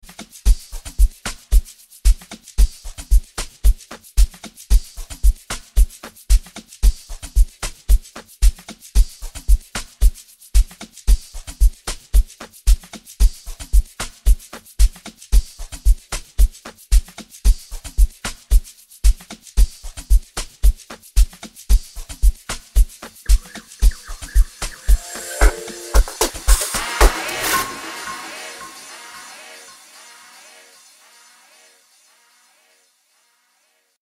Home » Amapiano